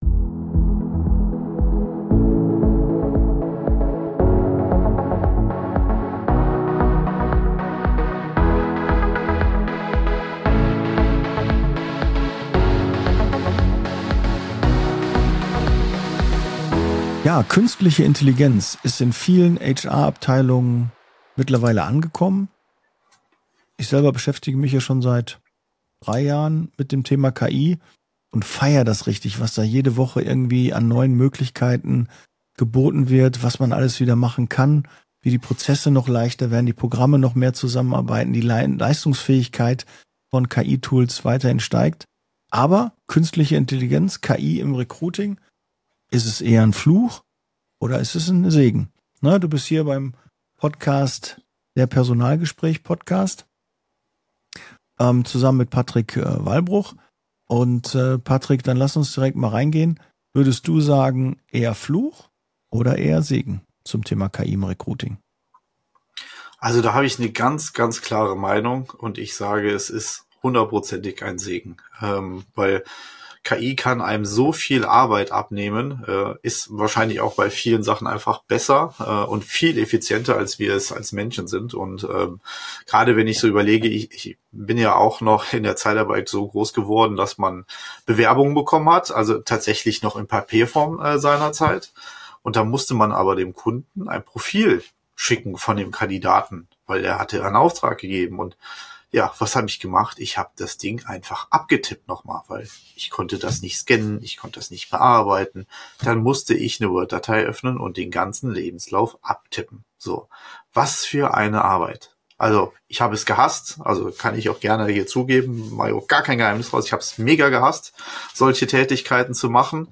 Der Personalgespräch Podcast – Zwei Profis, ein Team.